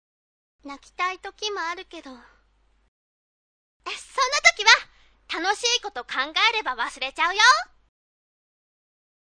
ヤエ-yae-　（♀）　14歳
陽気・元気・やる気のボジティブ人間。